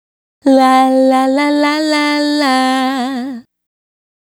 La La La 110-C.wav